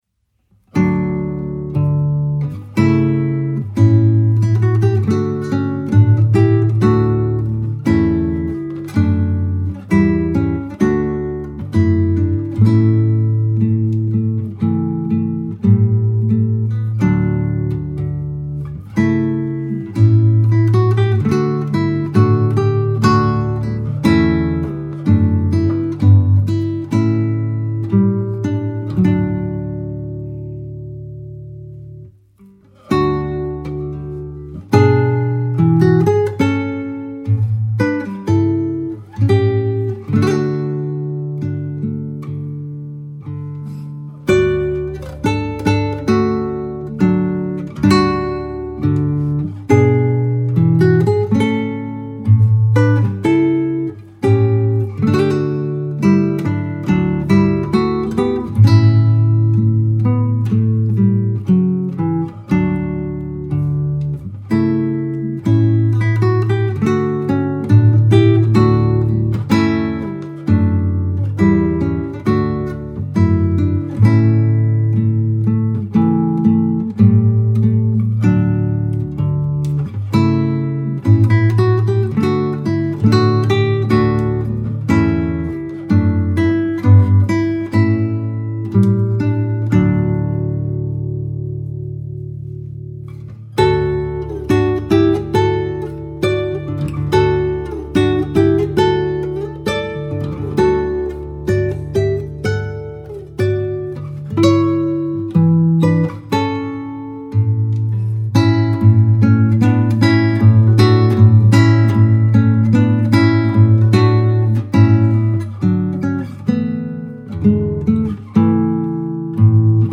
Classical Acoustic Solo Guitarist